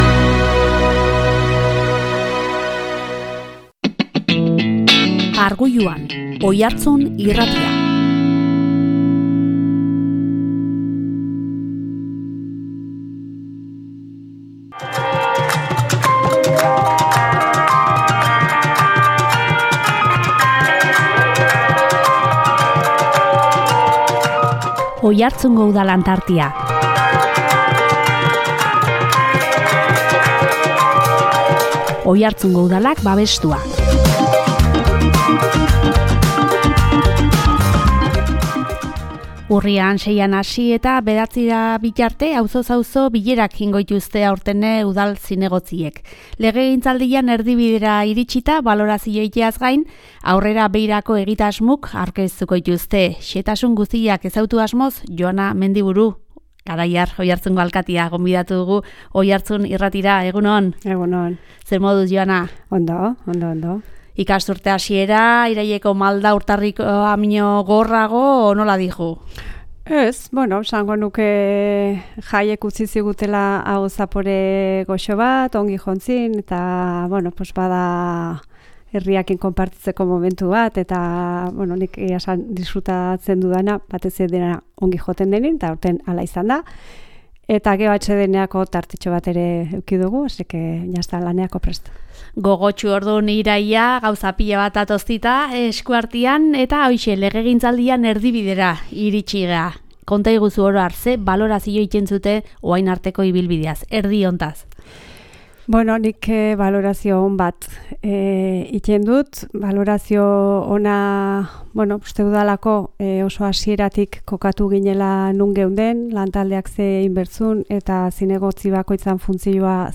Urriaren 6an hasi eta 9ra bitarte auzoz auzo bilerak egingo dituzte aurten ere Udal zinegotziek. Legegintzaldiaren erdibidera iritsita, balorazioa egiteaz gain, aurrera begirako egitasmoak aurkeztuko dituzte. Xehetasun guztiak ezagutu asmoz Joana Mendiburu Garaiar, Oiartzungo alkatea gonbidatu dugu Oiartzun Irratira.